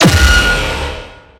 railgun-turret-gunshot-4.ogg